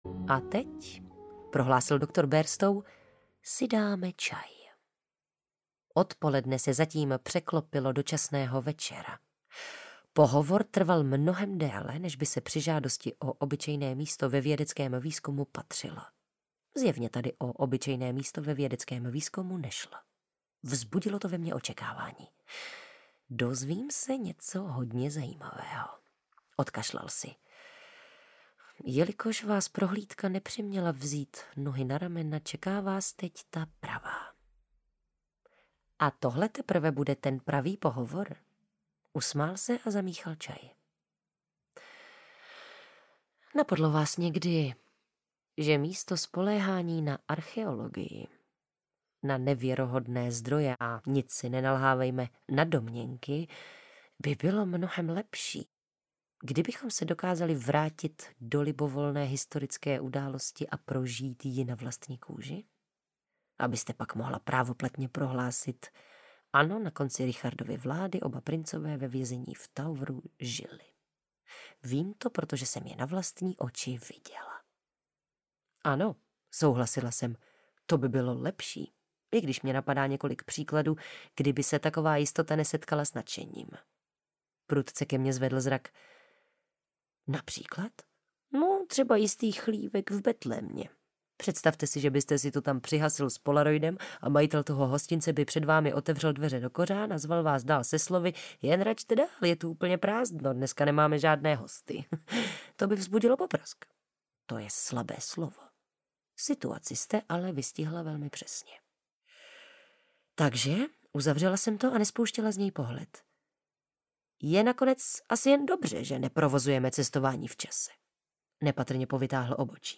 Délka: 5 h 41 min Interpret: Vojtěch Kotek Vydavatel: Tympanum Vydáno: 2021 Série: Stopařův průvodce Galaxií díl 4 Jazyk: český Typ souboru: MP3 Velikost: 321 MB
Audioknihy